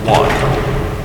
announcer_begins_1sec.mp3